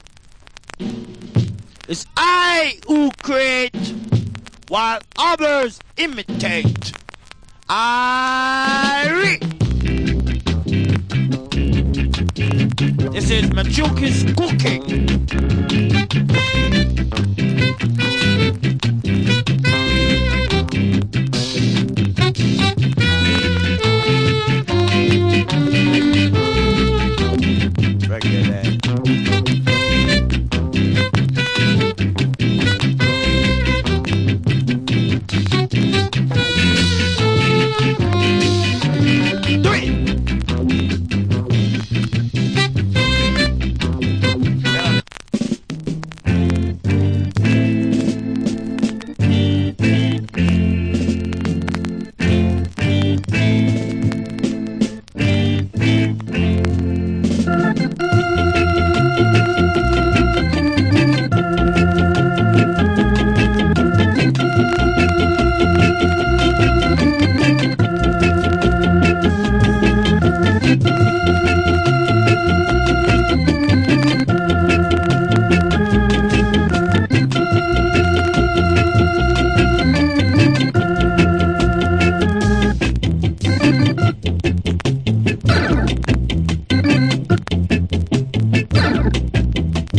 Wicked Early Reggae Inst.